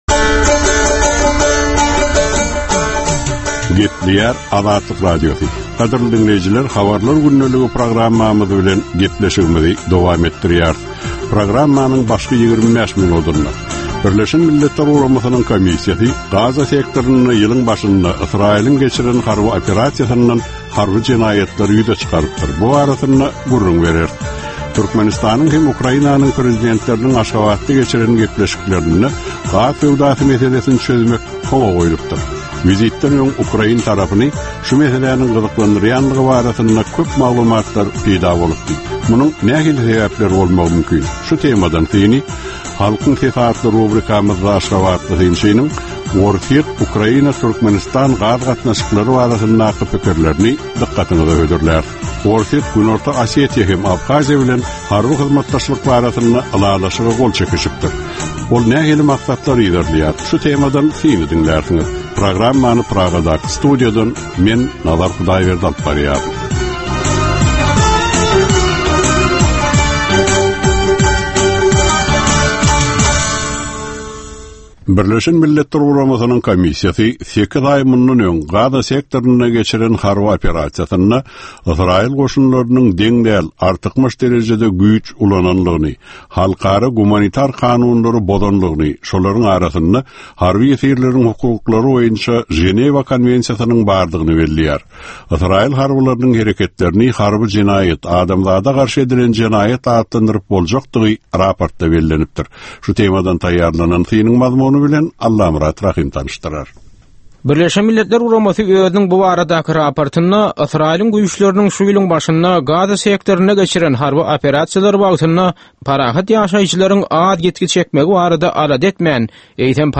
Türkmenistandaky we halkara arenasyndaky soňky möhüm wakalar we meseleler barada ýörite informasion-analitiki programma. Bu programmada soňky möhüm wakalar we meseleler barada giňişleýin maglumatlar, analizler, synlar, makalalar, söhbetdeşlikler, reportažlar, kommentariýalar we diskussiýalar berilýär.